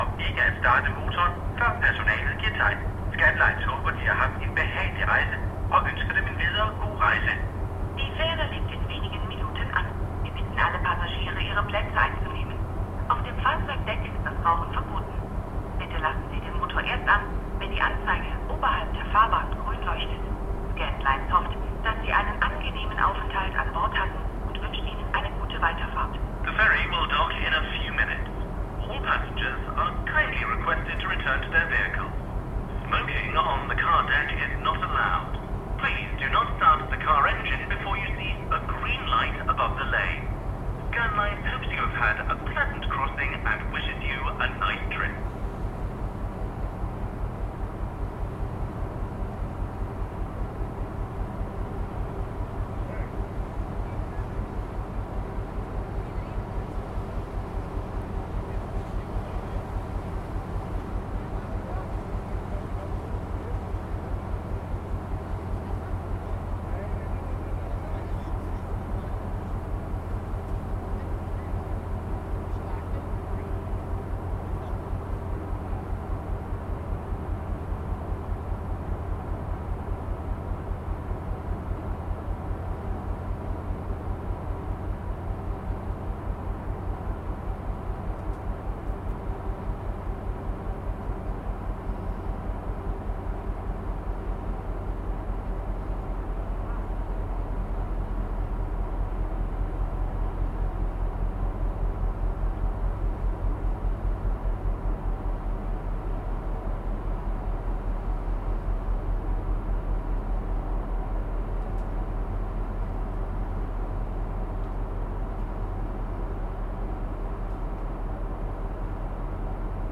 Ship arrives into Rodby, Denmark
Field recording
the ship from Puttgarden to Rødby crossing the Fehmarnbelt, June 2015.